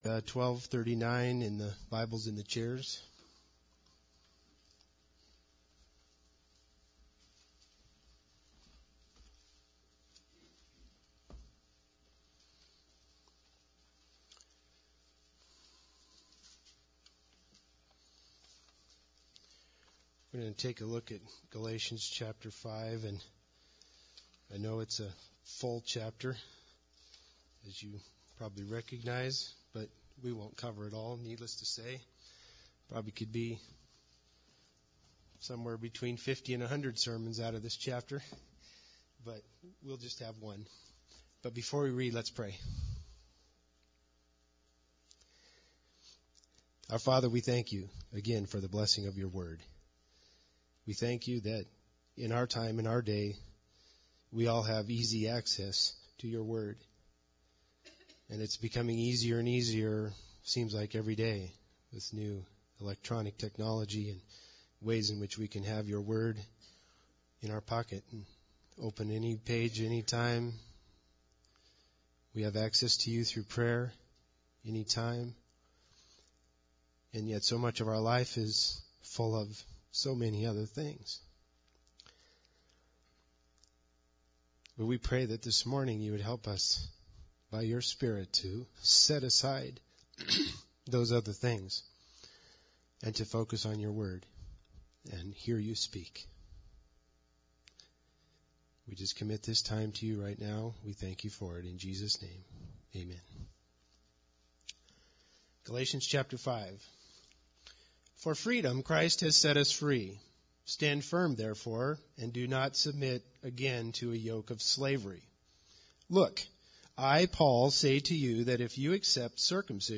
Galatians 5 Service Type: Sunday Service Bible Text